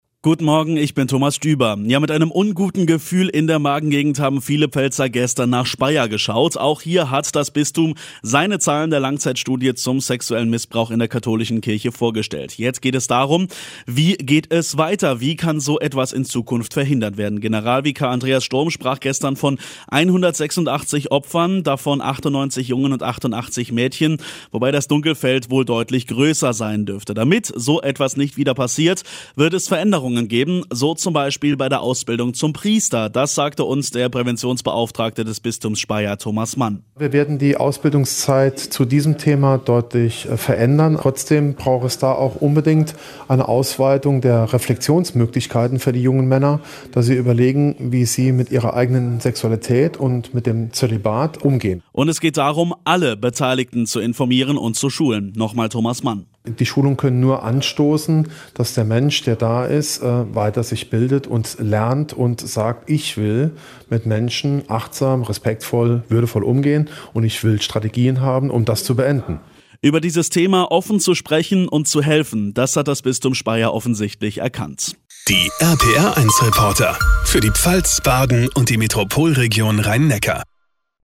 Prävention im Bistum Speyer - Beitrag